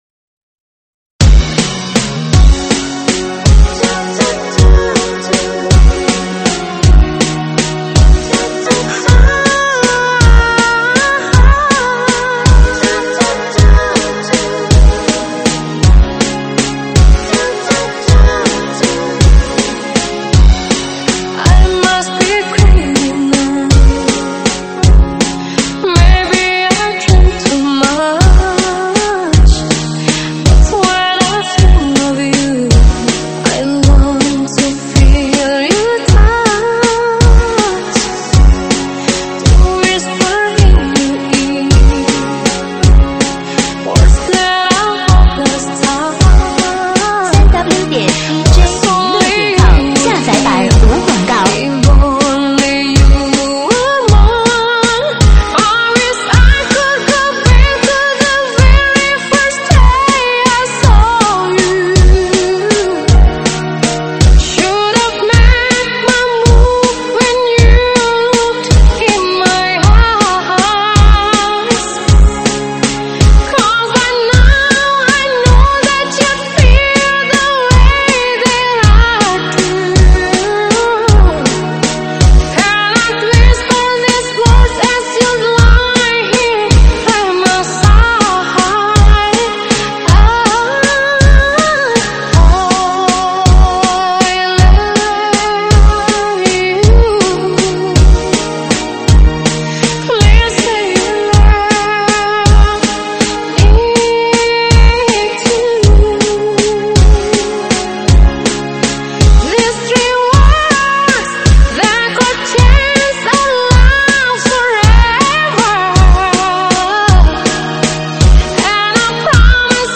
舞曲类别：快三